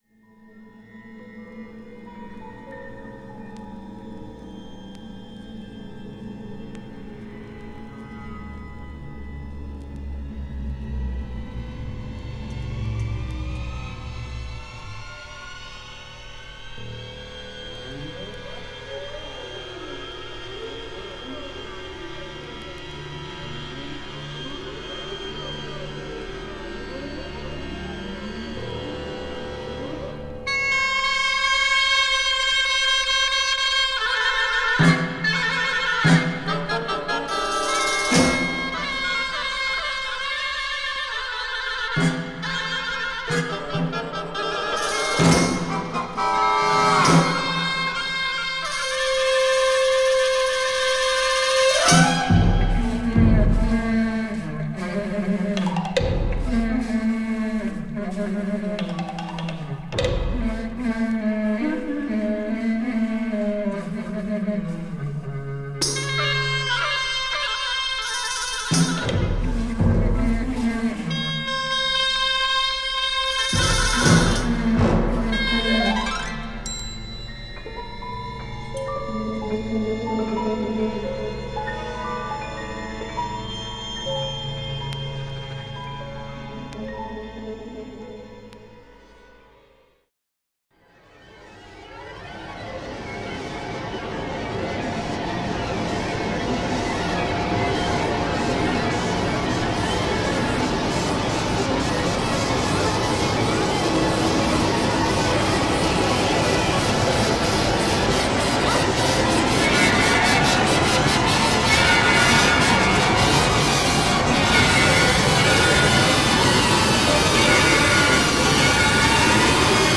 SOUND TRACK
試聴は僕のレコードを使って録音しておきます。